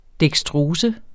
Udtale [ dεgsˈtʁoːsə ]